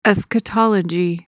PRONUNCIATION:
(es-kuh-TOL-uh-jee)